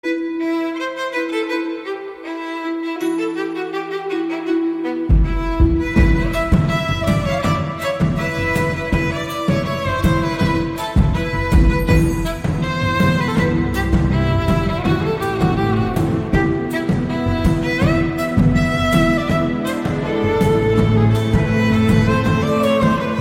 CategoryTamil Ringtones